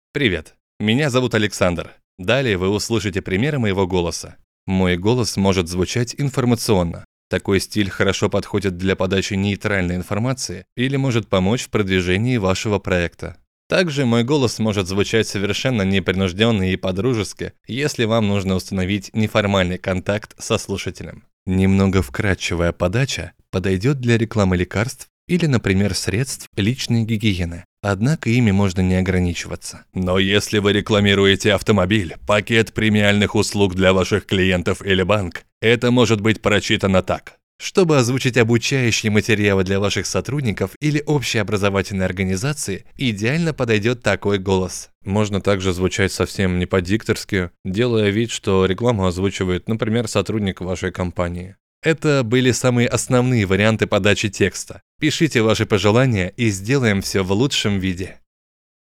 Пример звучания голоса
Муж, Рекламный ролик/Зрелый
Довольно низкий и приятный бас, который может звучать как мягко, так и агрессивно.
Focusrite Scarlett Solo 3rd / Shure MV7X + KLARK CT1 / Sennheiser HD 280 PRO